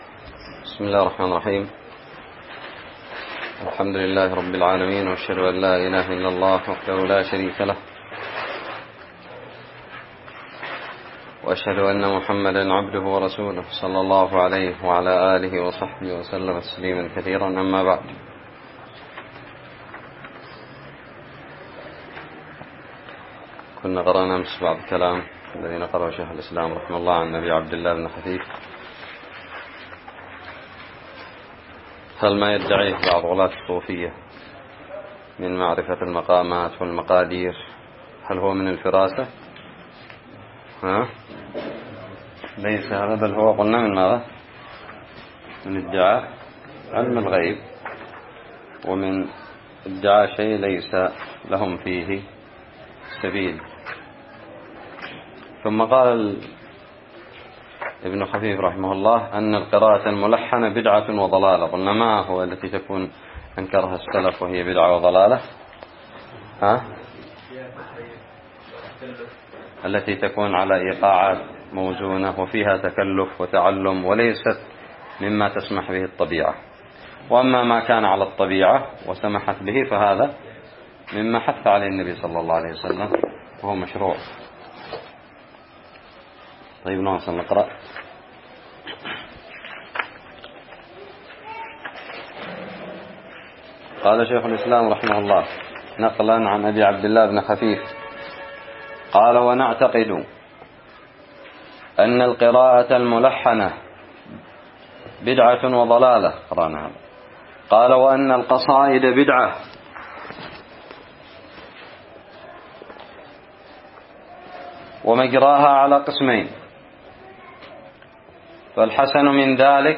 الدرس التاسع عشر من شرح متن الحموية
ألقيت بدار الحديث السلفية للعلوم الشرعية بالضالع